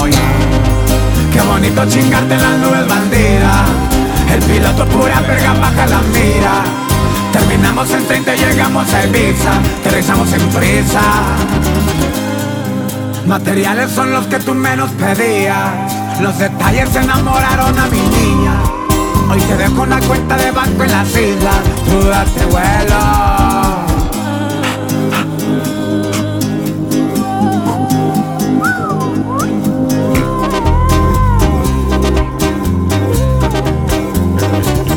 Скачать припев
Música Mexicana